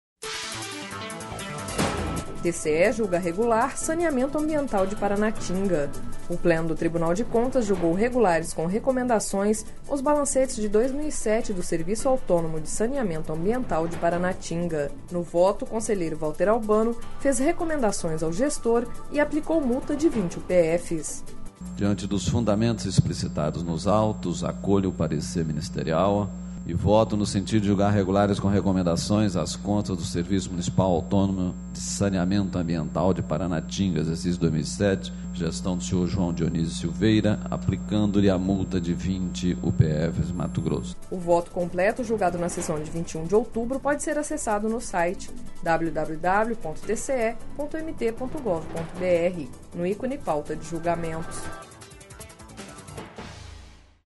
Sonora: Valter Albano – conselheiro do TCE-MT